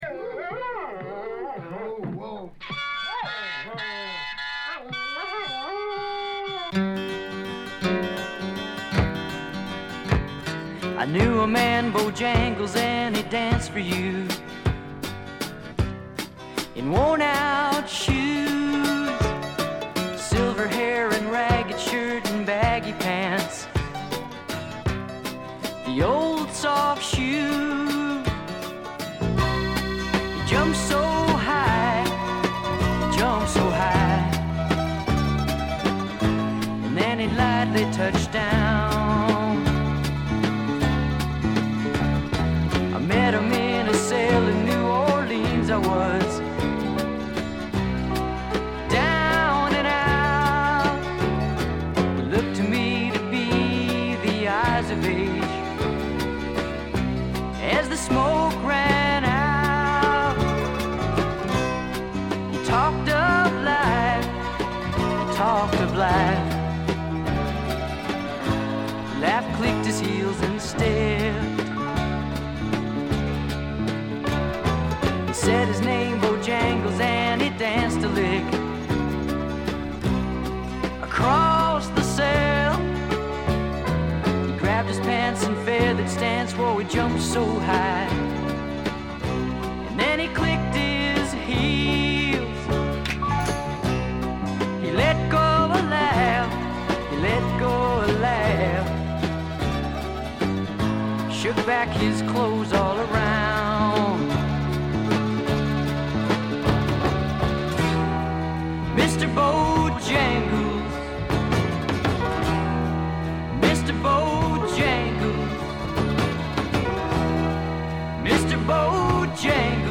部分試聴ですが、チリプチ少々。
試聴曲は現品からの取り込み音源です。